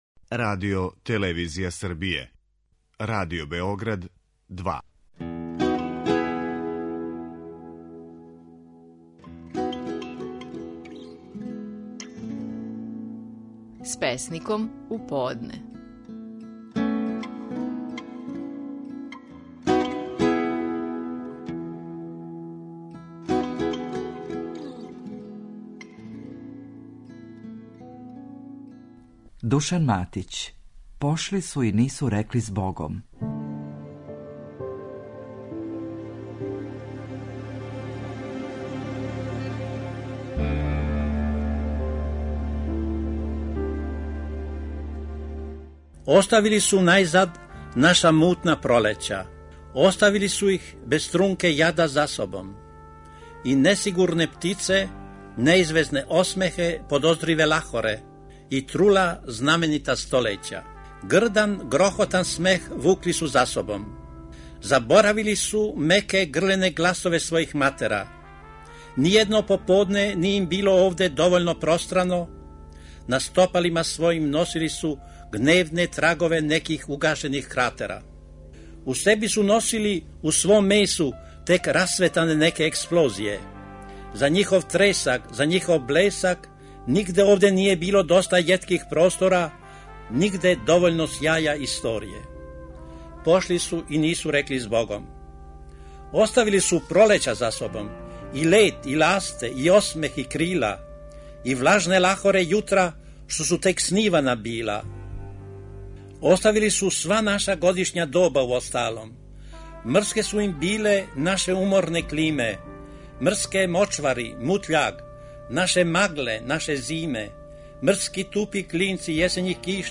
Стихови наших најпознатијих песника, у интерпретацији аутора.
Душан Матић говори песму „Пошли су и нису рекли збогом".